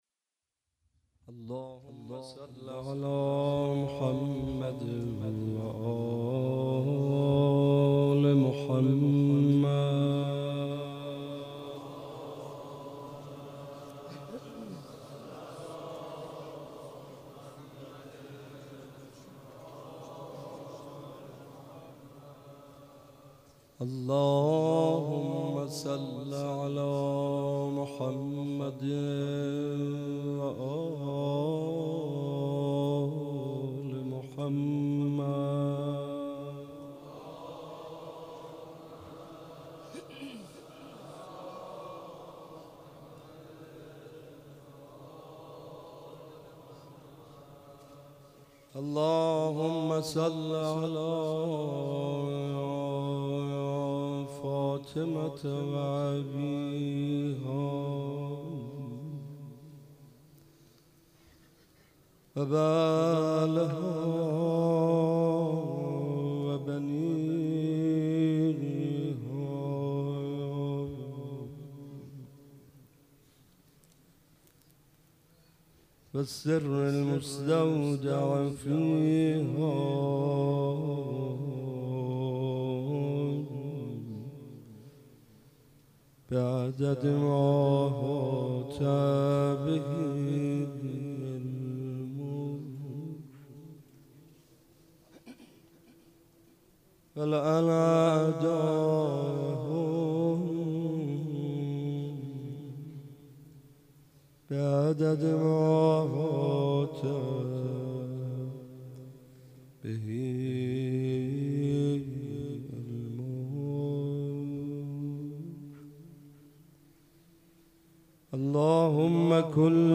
شب پنجم رمضان95، حاج محمدرضا طاهری